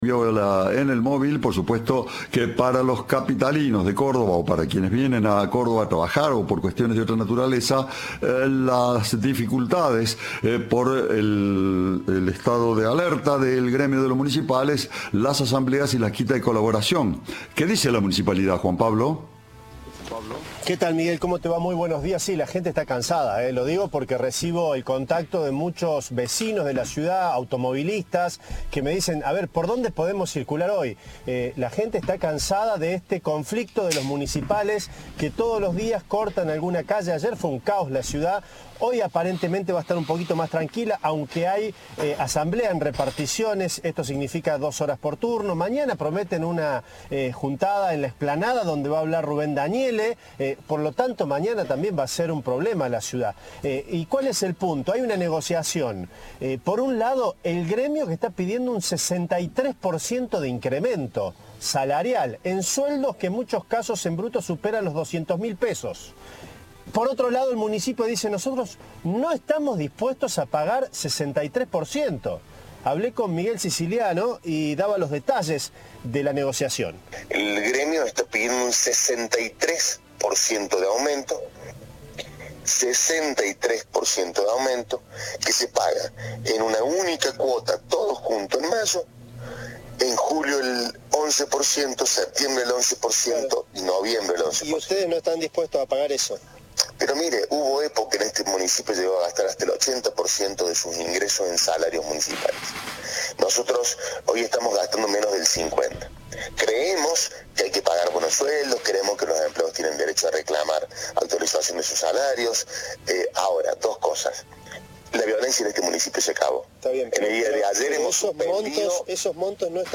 "No vamos a desfinanciar la ciudad", dijo a Cadena 3 el secretario de Gobierno, Miguel Siciliano.
Informe